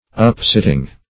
Search Result for " upsitting" : The Collaborative International Dictionary of English v.0.48: Upsitting \Up"sit`ting\, n. A sitting up of a woman after her confinement, to receive and entertain her friends.